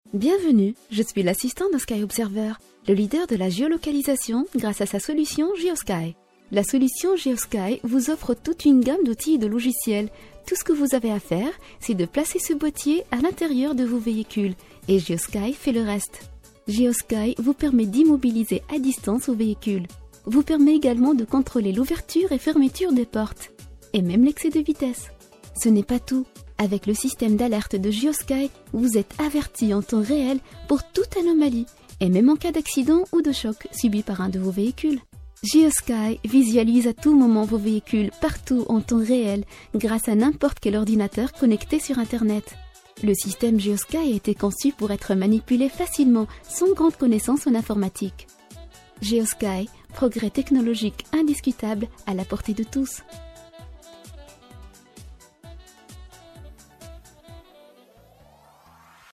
Votre voix-off arabe, promos, pub, documentaires...
Sprechprobe: eLearning (Muttersprache):